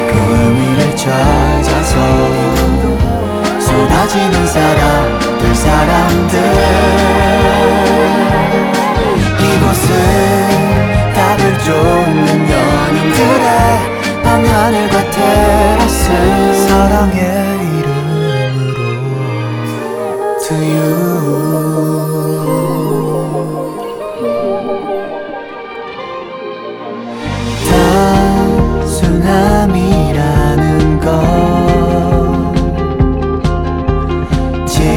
K-Pop Pop Rock
Жанр: Поп музыка / Рок